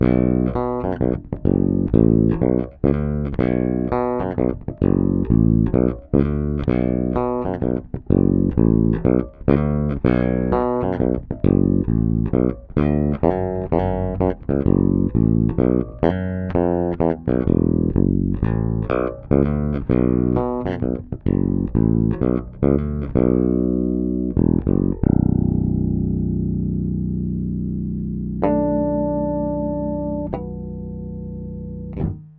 Nahrávky jsou bez kompresoru, linka do zvukovky.
Trochu je slyšet brum, ale to mi dělá dnes elektrika doma.